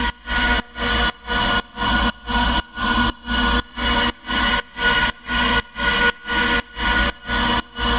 Index of /musicradar/sidechained-samples/120bpm